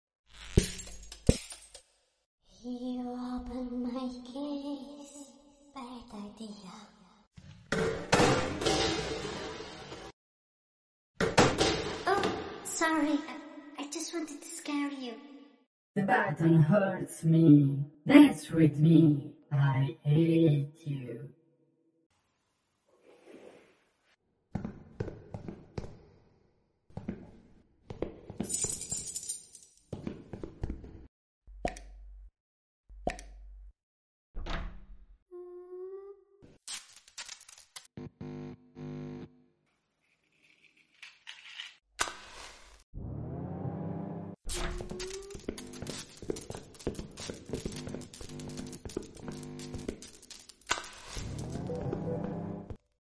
Cool Poppy Playtime sound effects sound effects free download